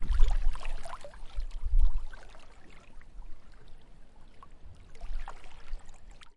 大不列颠 " 柔软的迷你小浪花
我自己用ZOOM H4录制。
Tag: 飞溅 沙滩 海洋 环境 海浪 海边 飞溅 海岸 沙滩 海边 声景观 自然 现场录音 岩石 夏天